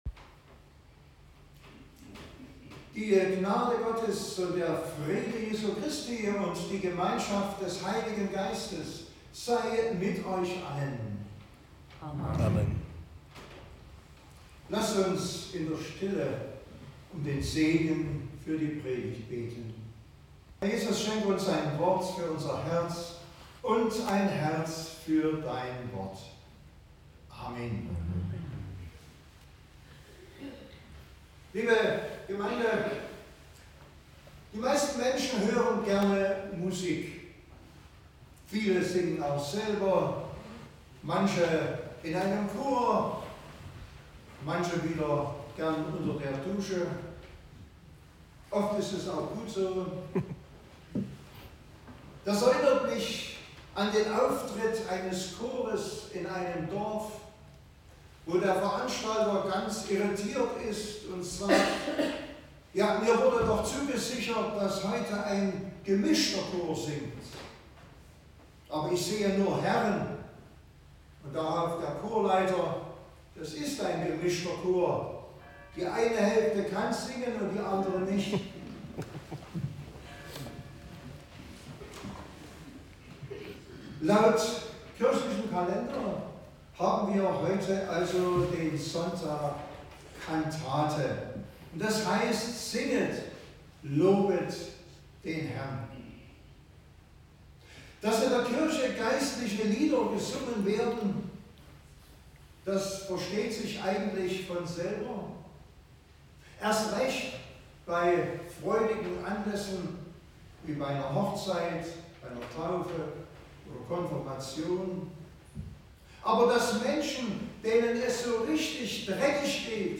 Predigtgottesdienst